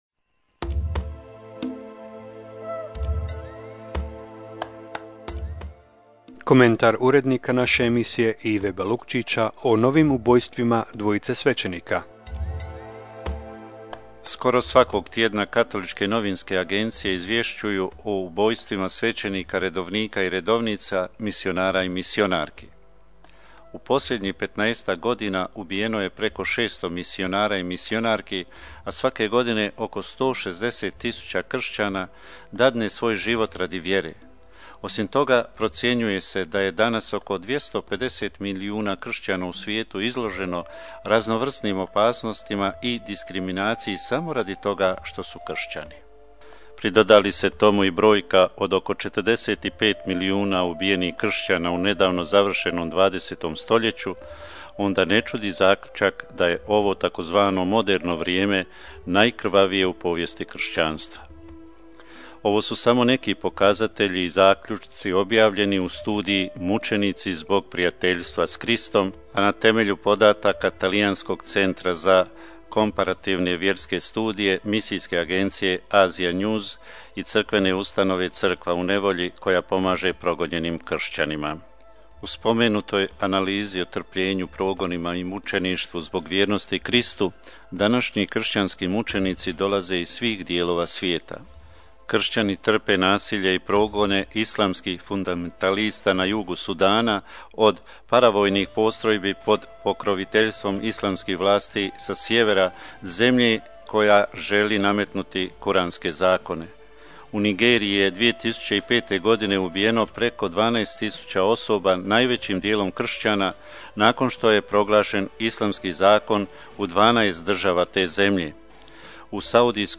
U emisiji Radio postaje Odžak "Glas vjere"